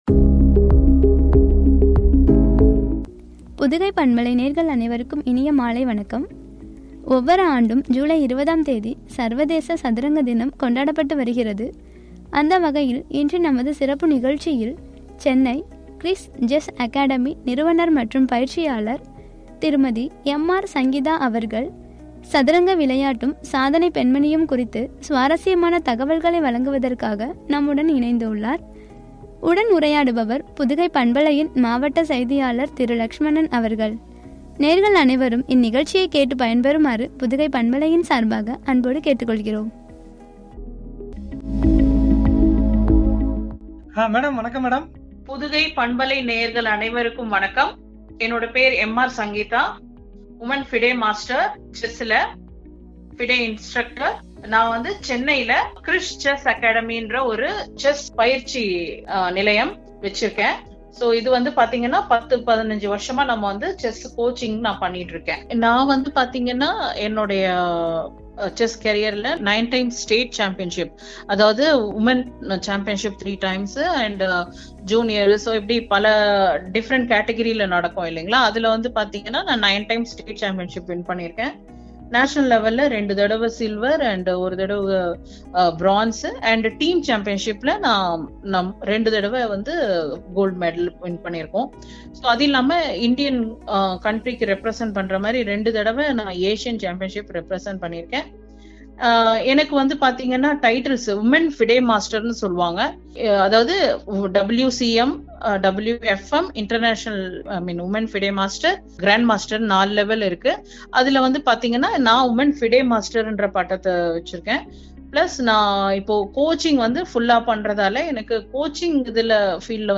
சாதனைப் பெண்மணியும்” எனும் தலைப்பில் பழகிய உரையாடல்.